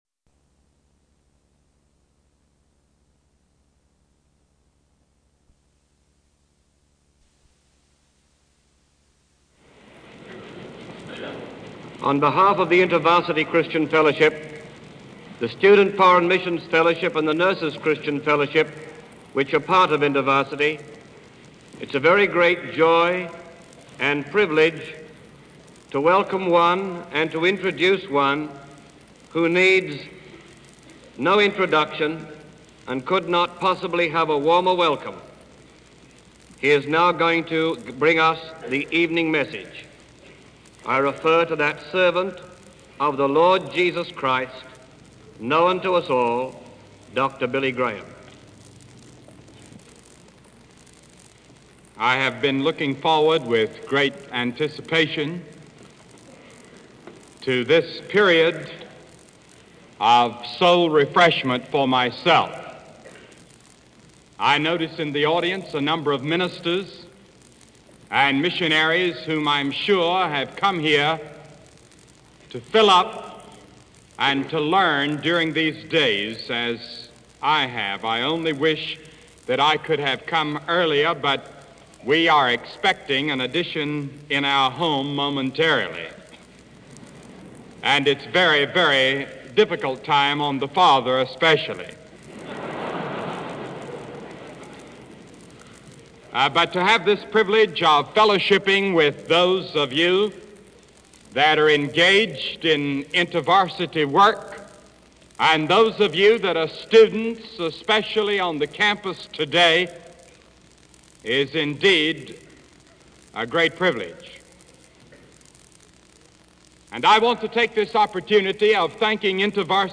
He was addressing the Urbana Conference of 1957. Conversion, or repent, means to turn around.
urbana-57-billy.graham-an.encounter.with.christ.mp3